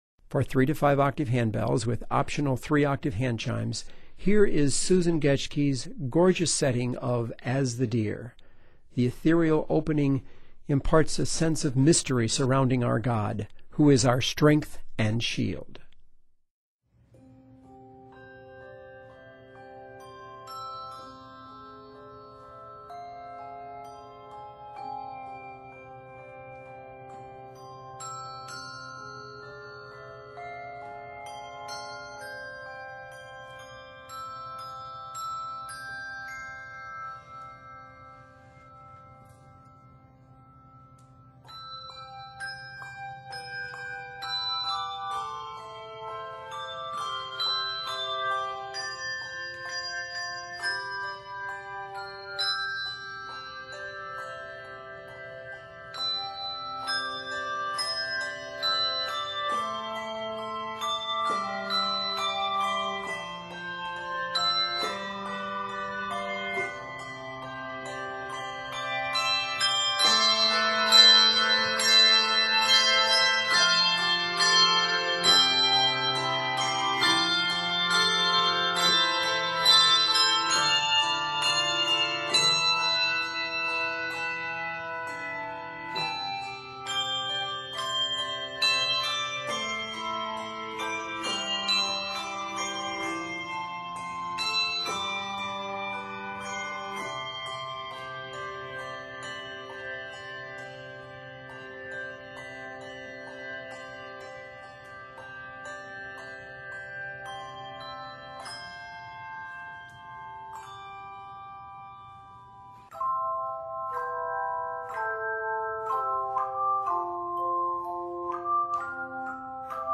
is scored in C Major and is 74 measures.